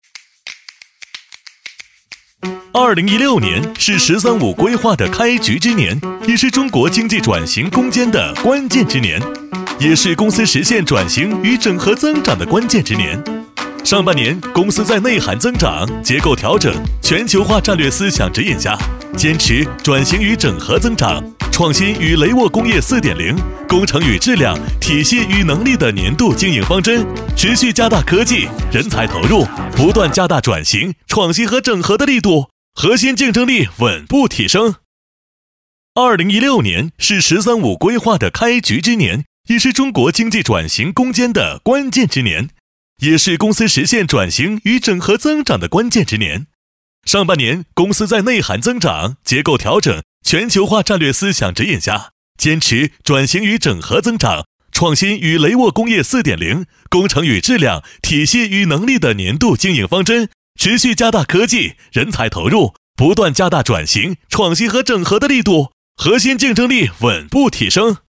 MG动画男138号
轻松自然 MG动画